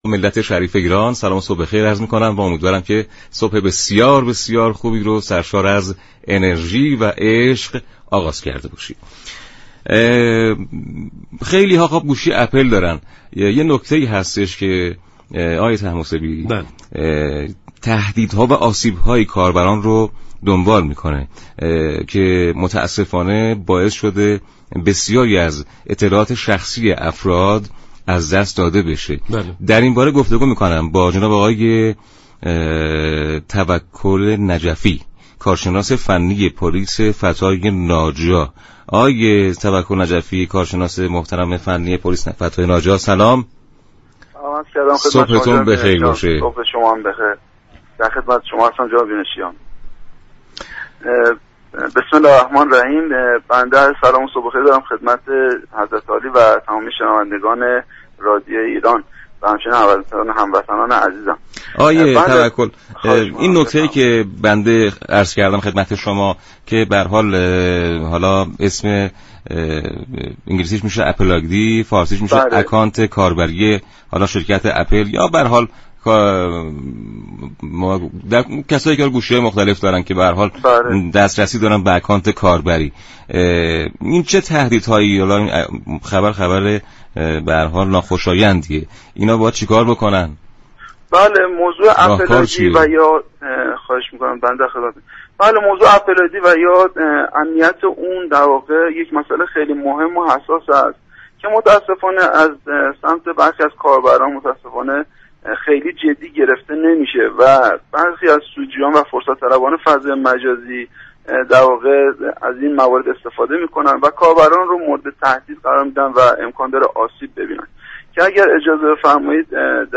كارشناس فنی پلیس فتای ناجا در گفت و گو با برنامه «سلام صبح بخیر» رادیو ایران گفت:كاربران، اگر هم از شناسه های سیستم عامل IOS استفاده می كنند حتما پرسش های امنیتی، گذر واژه و تاریخ تولد خود را تغییر دهند.